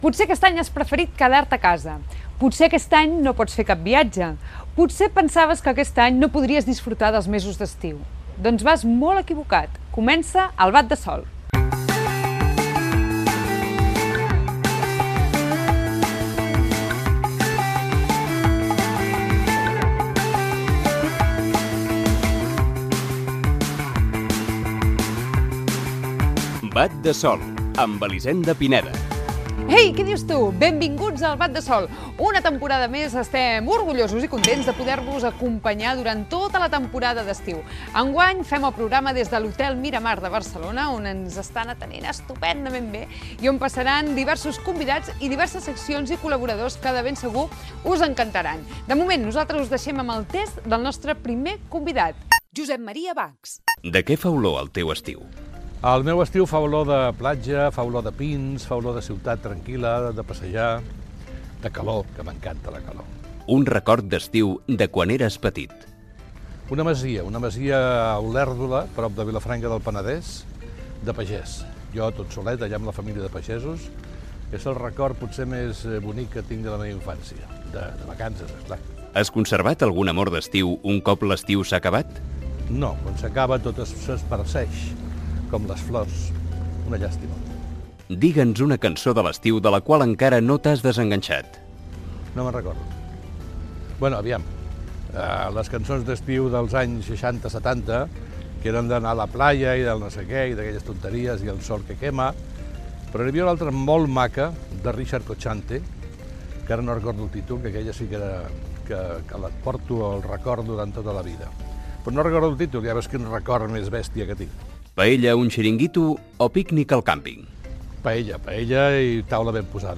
Identificació del programa, presentació des de l'Hotel Miramar de Barcelona.
Entreteniment